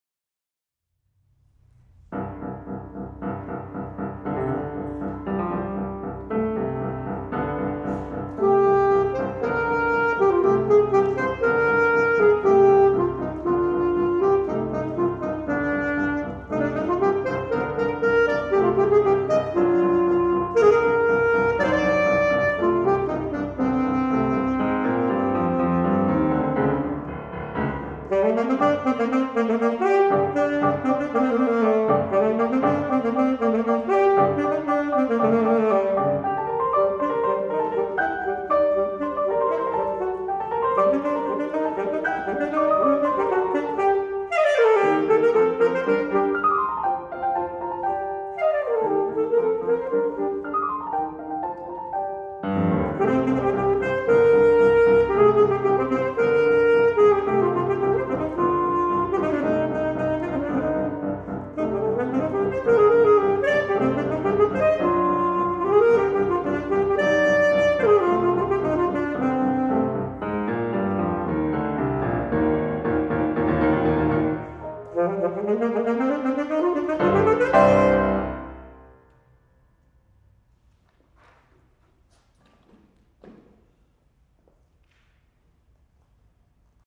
The first example that I will include is evidence of my senior recital.
Tableaux de Provence, movement III by Paule Maurice.  I have studied saxophone at Kansas State for nine semesters and given two hour-long solo recitals on saxophone.  This recital was my second senior recital, occurring the final semester before student teaching.